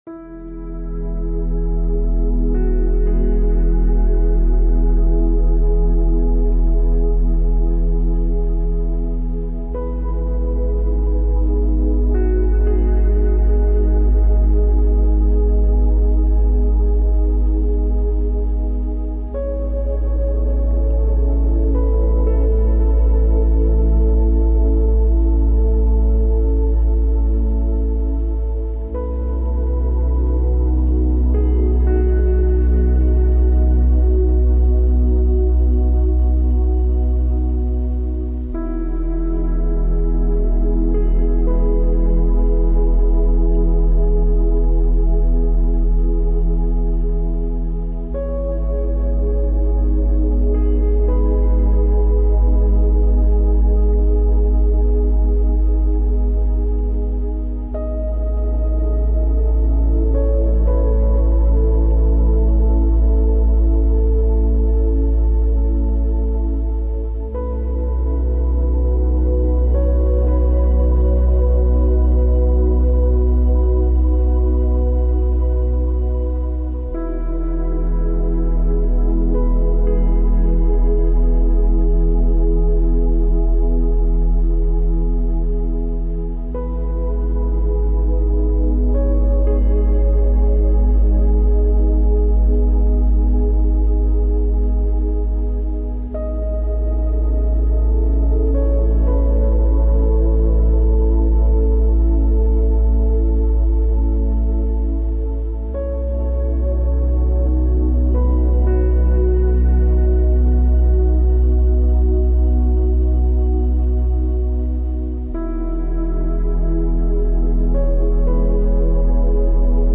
Meditation Frequencies – Quickly Open Your Chakras, Calm Your Mind and Find Peace | Balance Your Energy and Reduce Anxiety
All advertisements are thoughtfully placed only at the beginning of each episode, ensuring you enjoy the complete ambient sounds journey without any interruptions.